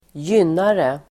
Uttal: [²j'yn:are]